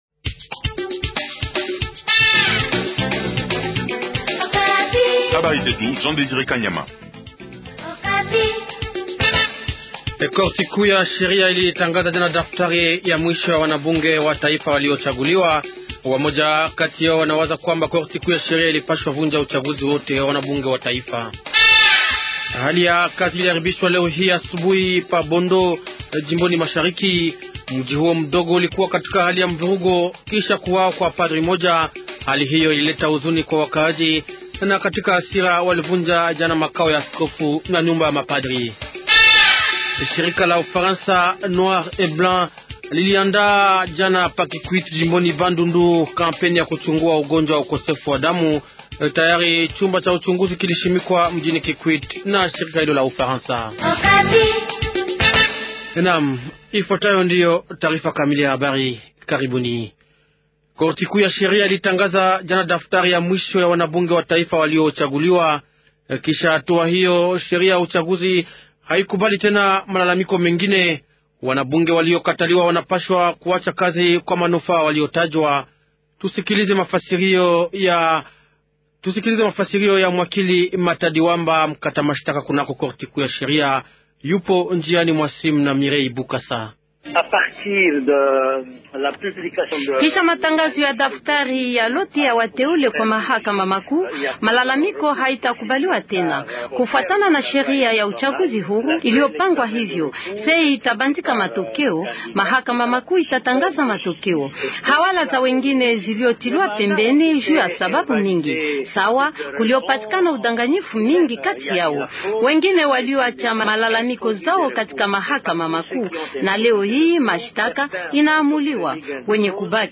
080507 journal swahili soir